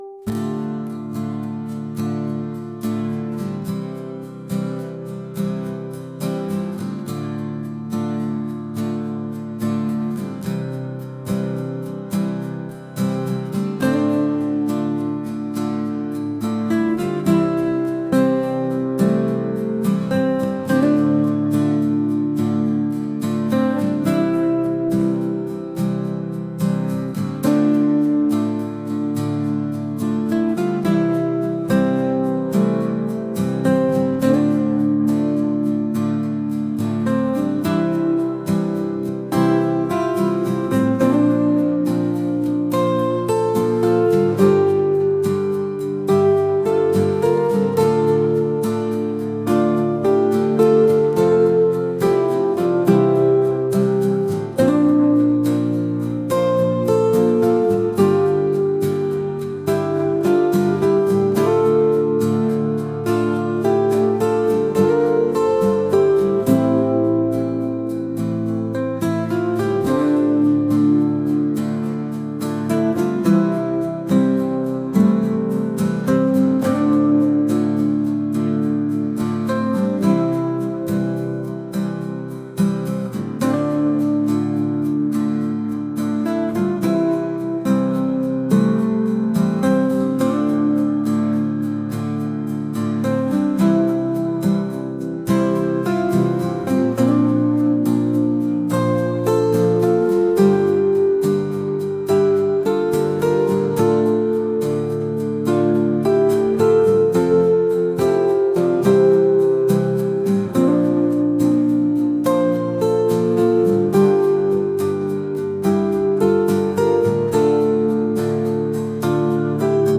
acoustic | ambient | folk